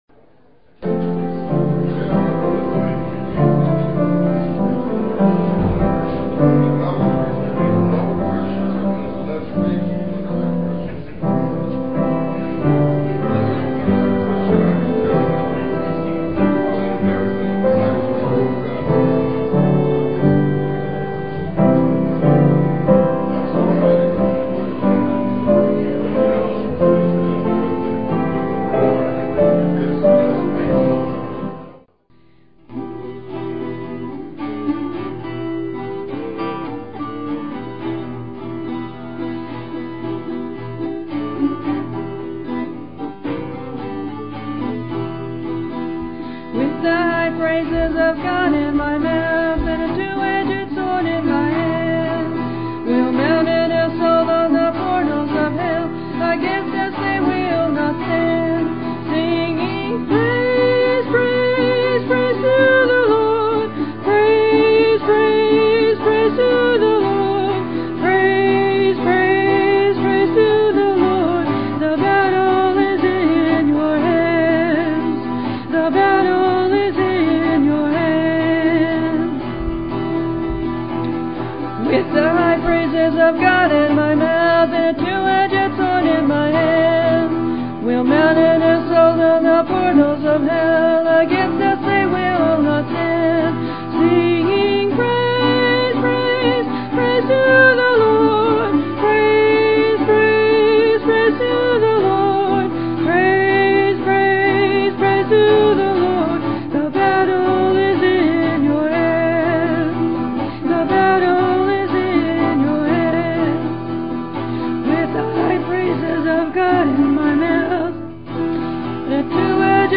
PLAY Joshua: Valiant in Fight, Part 3, Jan 21, 2007 Scripture: Joshua 3:1-17. Scripture reading
Piano and organ duets
Guitar and vocal solo